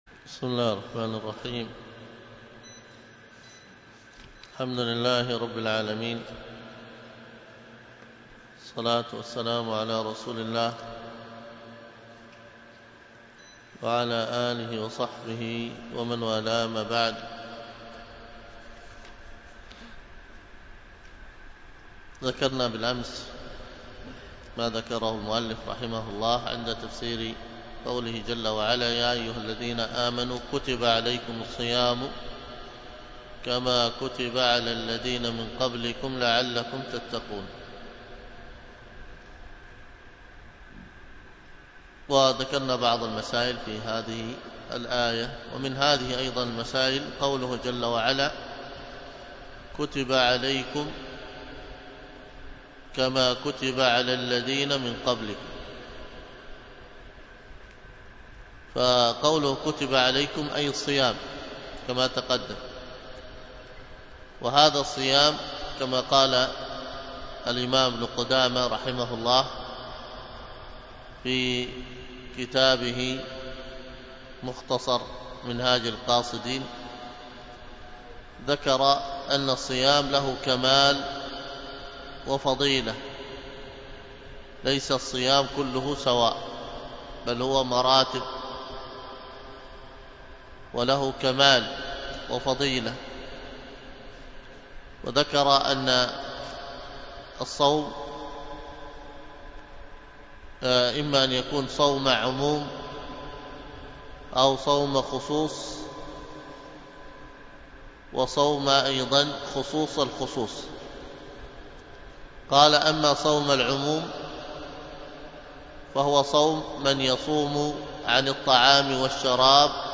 الدرس في آثار وآداب الصيام 19، ألقاها